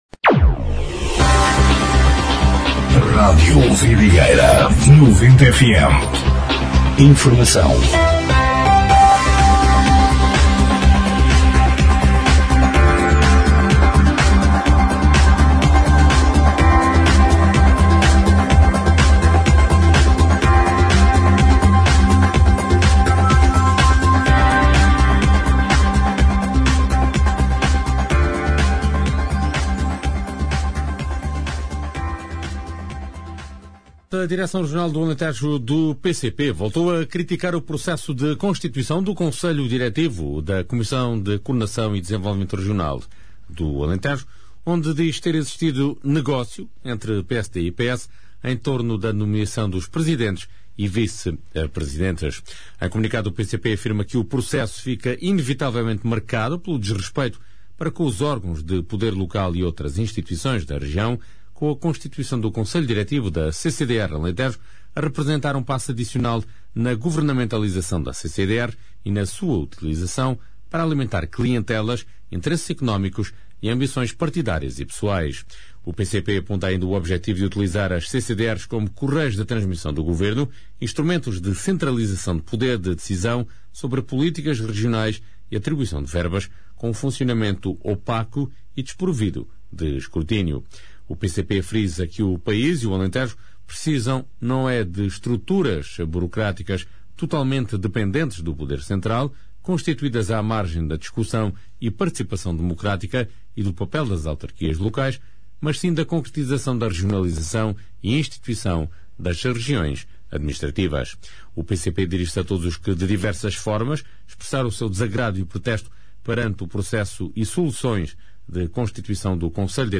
Noticiário 02/03/2026